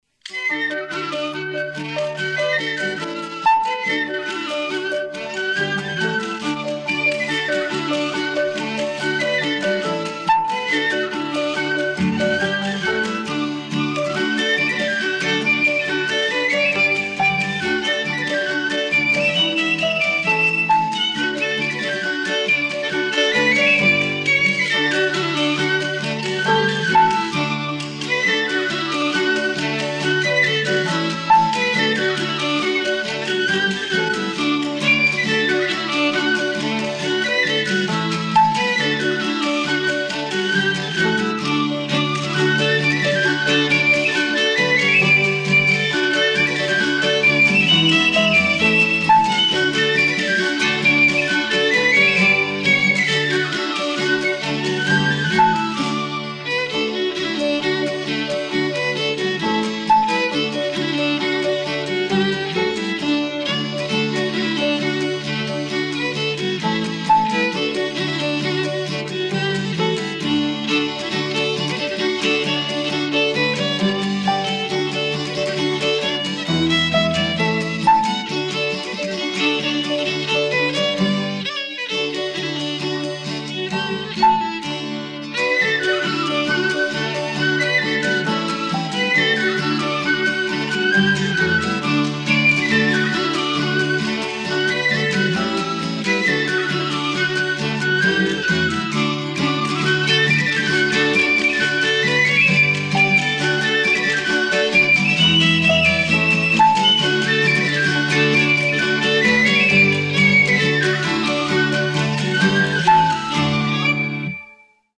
Traditional music ensemble
These tracks were recorded in a church hall at West Kilbride, North Ayrshire, around 1982:
guitar, recorder
clarsach
cello, washboard
flute, piccolo
raretunes-johnnie-i-do-miss-you-live.mp3